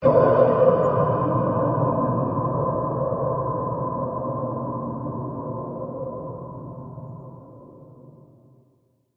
歌唱碗3（小）/Klangschale 3
描述：只是一个简单，清晰的唱歌碗:)
标签： 巴生 佛教 打击乐 唱歌碗 金属 金属 Klangschale 声音
声道立体声